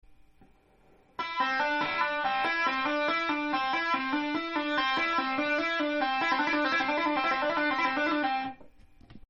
ライトハンド奏法フレーズ３
タッピングフレーズ３は、ドとレとミを使用しているので
よりマイナー感が強いフレーズになっています。